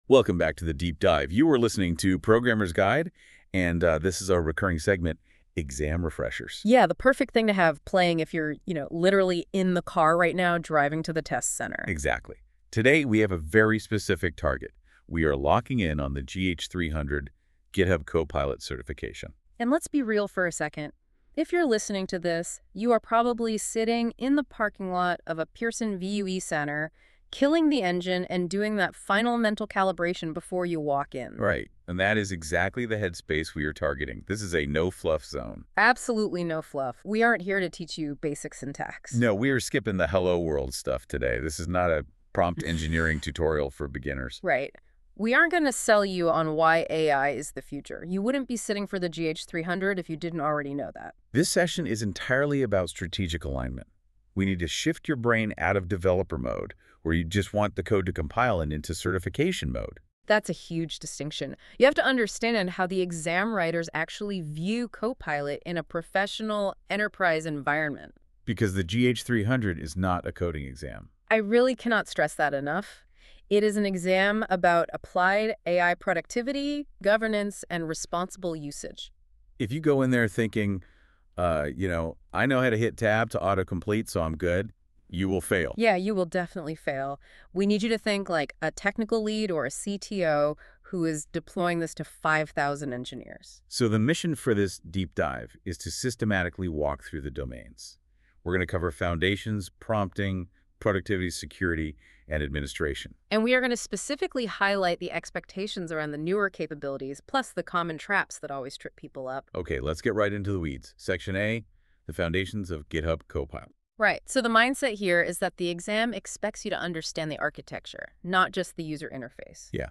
✨ Generated by NotebookLM
A podcast-style walkthrough of key exam tactics. Made to listen on the drive to the exam center as a last-minute refresher.